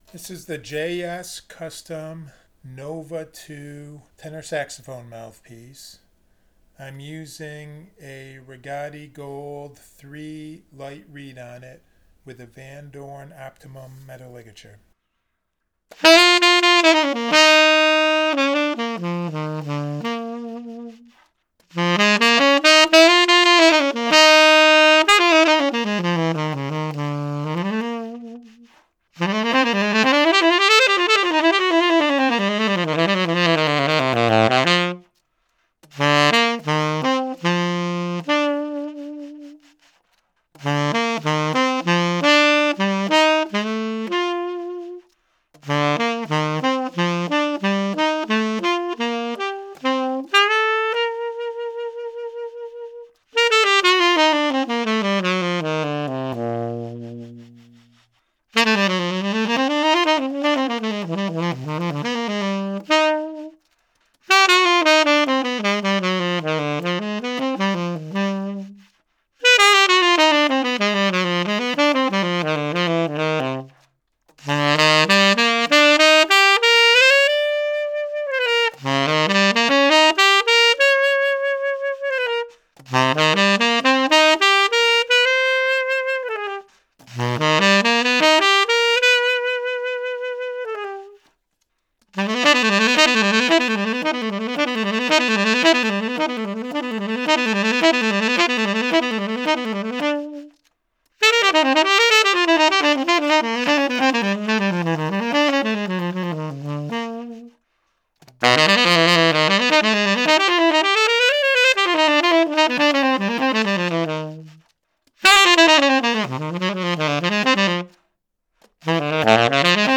The third sound clip is the same as the first 4′ 30″ sound clip with no reverb added to the mix.  This is a dry recording so you can hear how the Nova tenor saxophone mouthpiece might sound in a dry room with no natural reverb in the room.
JS Custom Nova 7* Tenor Saxophone Mouthpiece – Same as the first clip with no reverb  –  Rigotti Gold 3 Light Reed